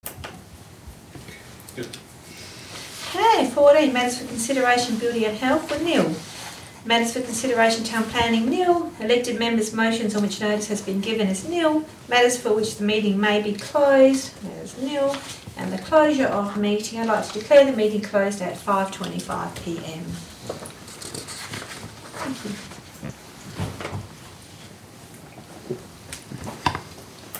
Location: Tammin Council Chambers